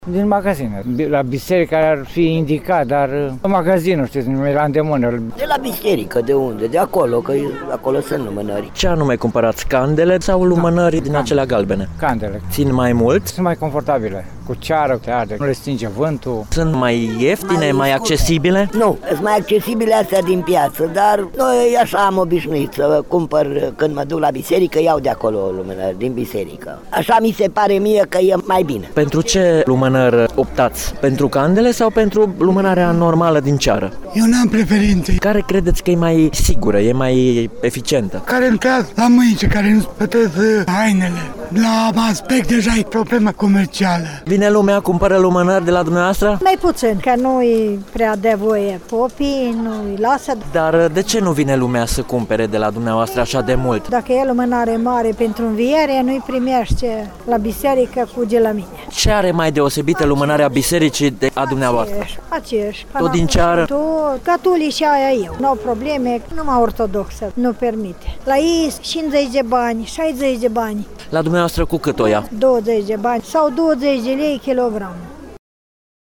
a vizitat câteva biserici din Reşiţa şi a aflat de la unii dintre cetăţeni de unde îşi vor cumpăra lumânările pentru noaptea de Înviere: